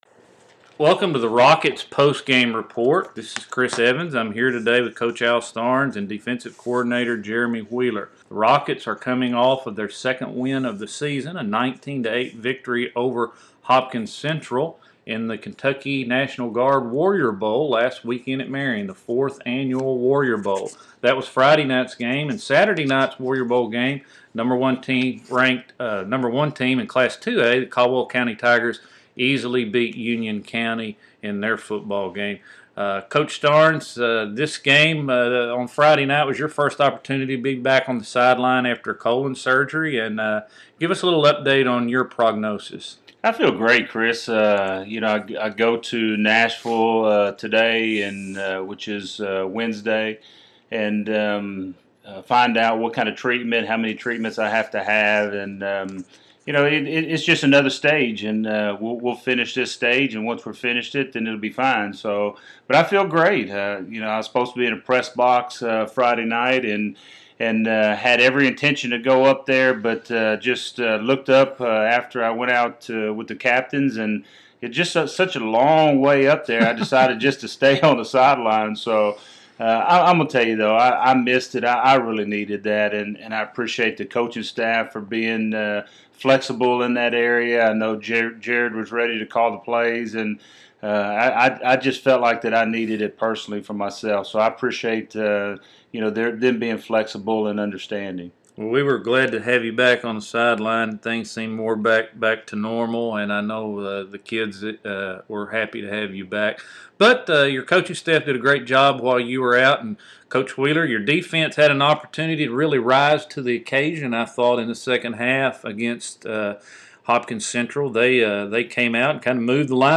Post-Game Interviews